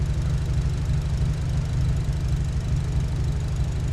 rr3-assets/files/.depot/audio/Vehicles/i4_05/i4_05_idle.wav
i4_05_idle.wav